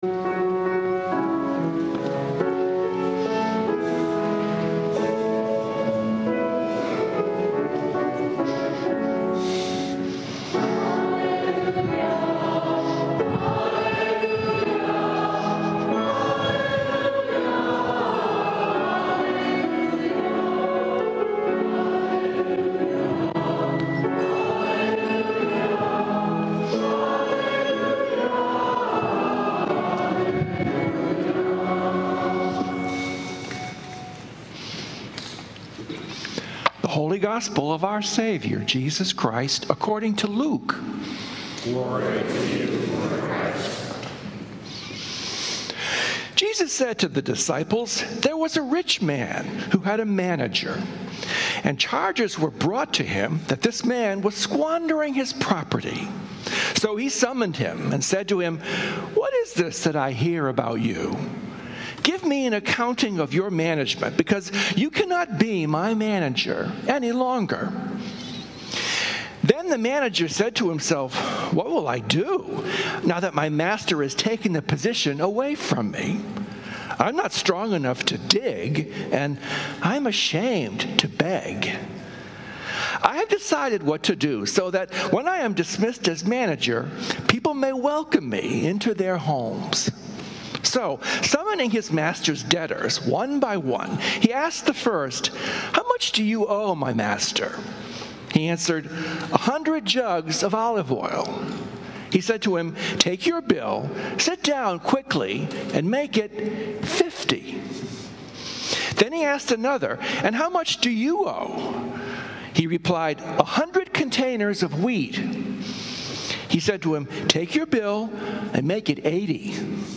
Sermons from St. Columba's in Washington, D.C. Sunday Sermon